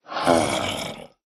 Minecraft Version Minecraft Version 25w18a Latest Release | Latest Snapshot 25w18a / assets / minecraft / sounds / mob / zombie_villager / say2.ogg Compare With Compare With Latest Release | Latest Snapshot